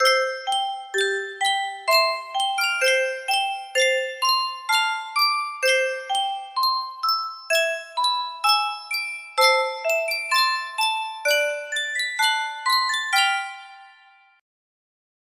Sankyo Music Box - Hail Purdue RJO music box melody
Full range 60